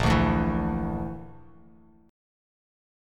A#Mb5 chord